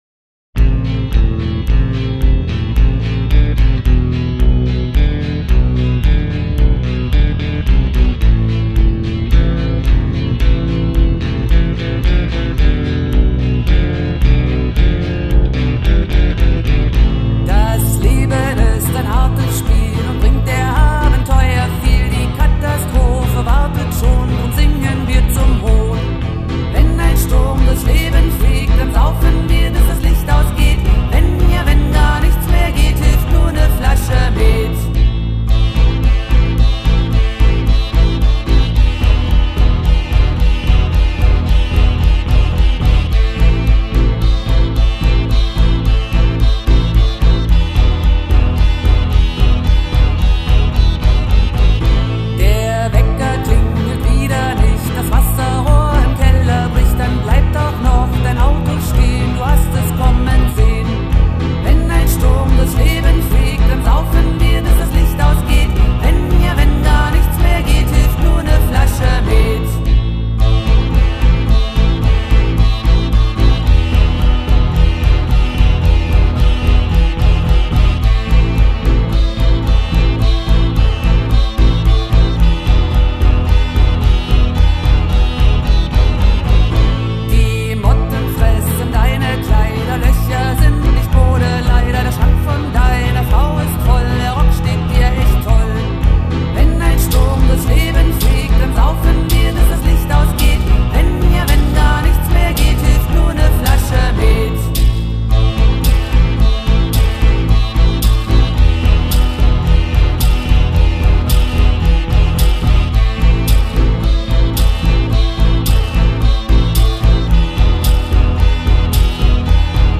Katastrophensong (Demo) (C) 2023 zur Übersicht
Gesang, Rassel